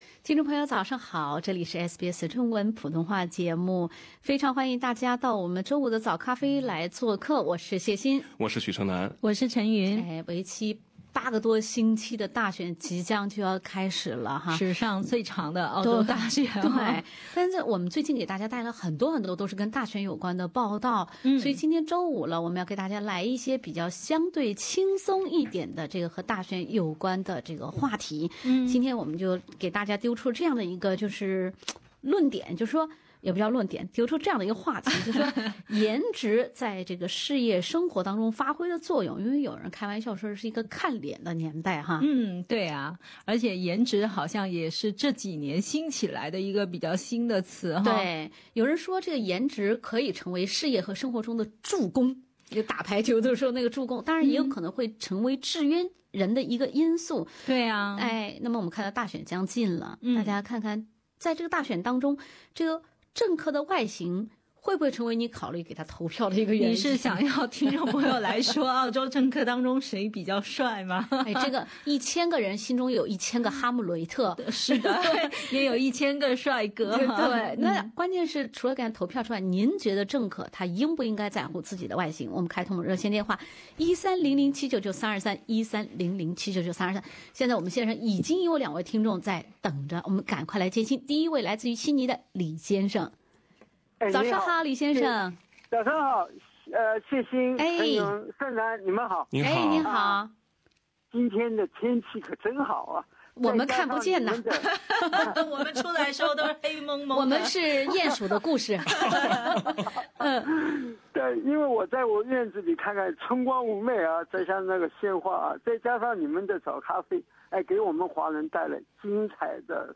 大選將近，政客的外形是您為他投票的葠考之一嗎？ 本期《早咖啡》節目，聽眾朋友與大家聊聊顏值與大選。